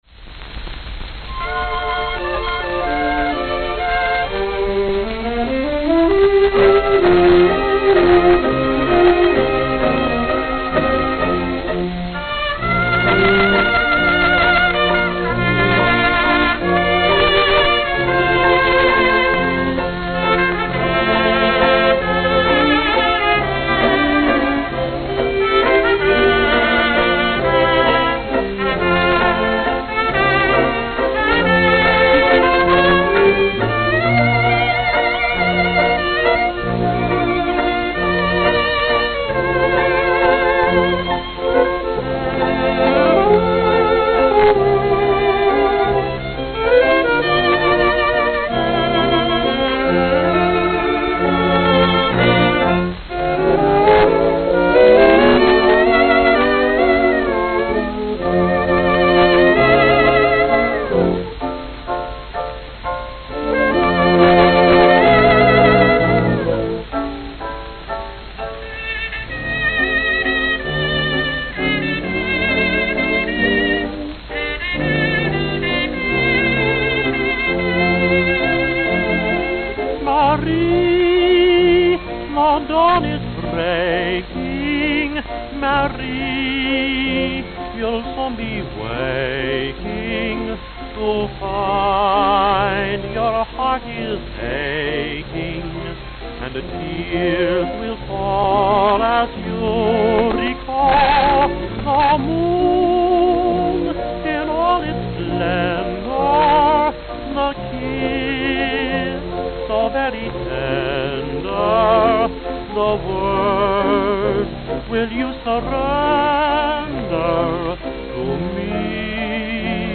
Note: Hairline crack, not audible.